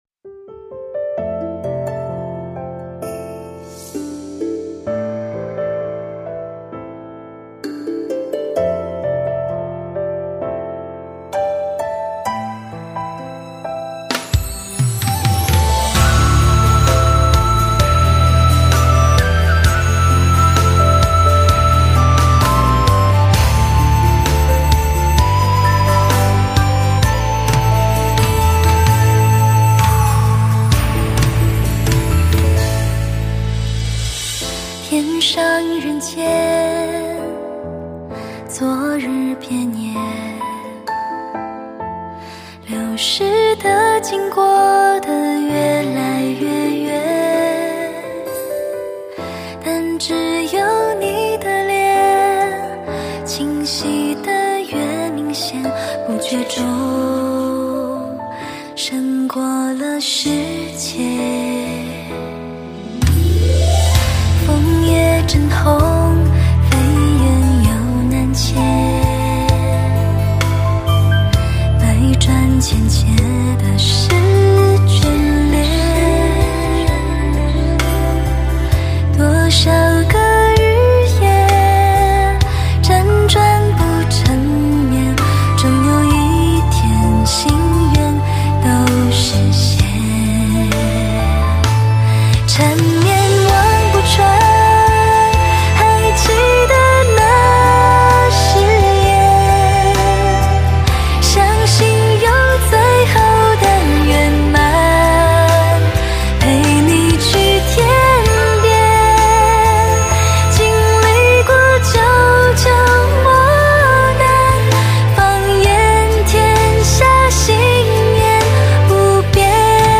11首 甜美情歌 + 5首抒情kala